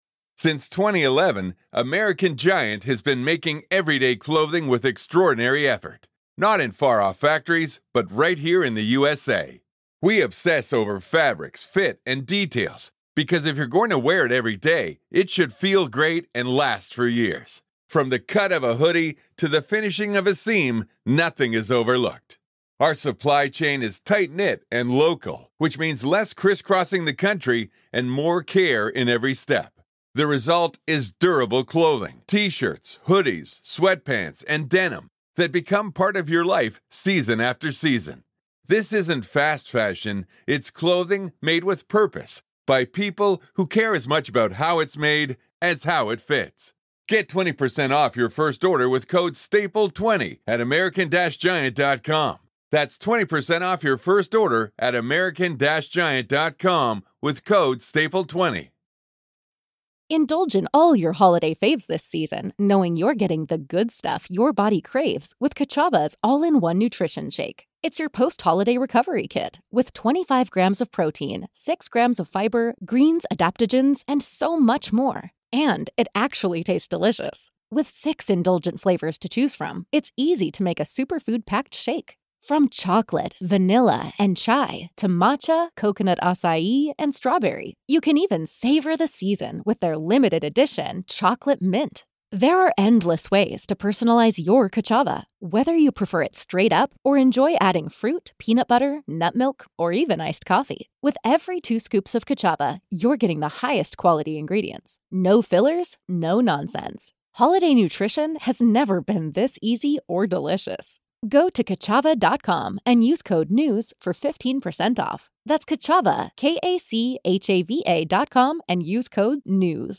NWZ Nachrichten Botcast – der tägliche News-Podcast aus dem Norden
Nachrichten